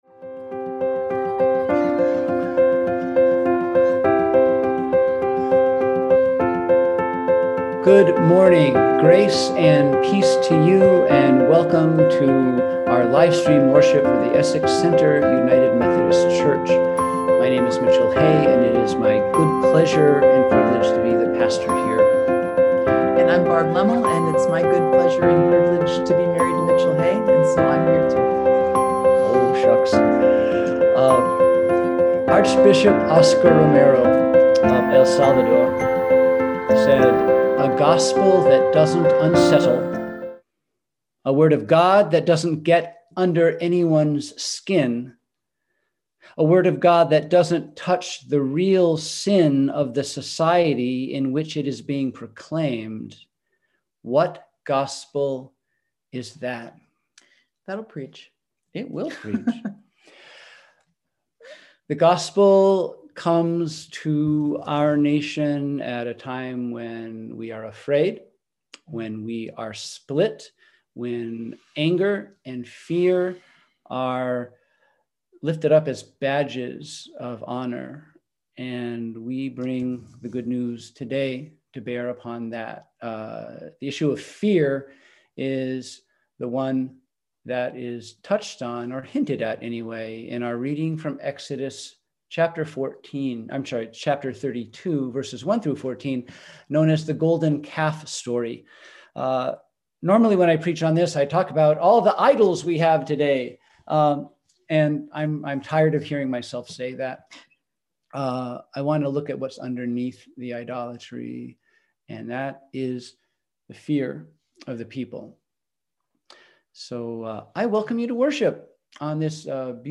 We held virtual worship on Sunday, October 11, 2020 at 10am.